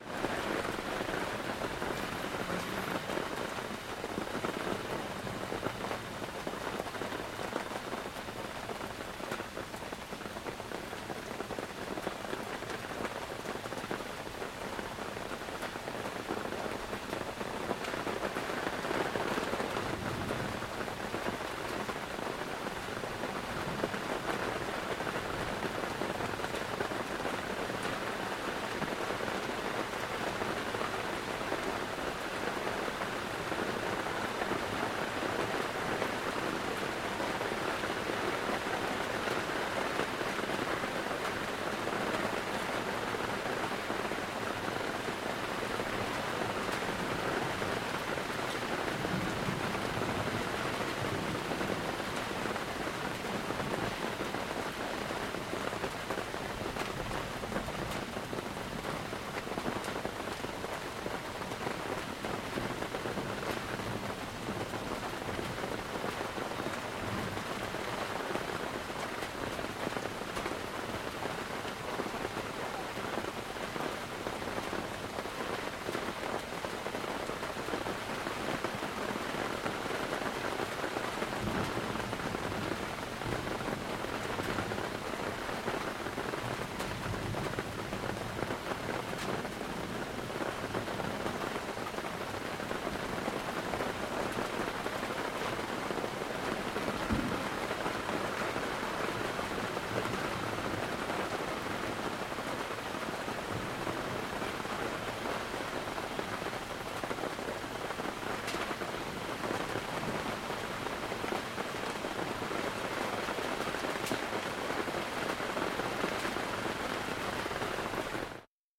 Шум дождя под брезентом палатки (запись изнутри)